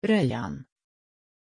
Aussprache von Rayyan
pronunciation-rayyan-sv.mp3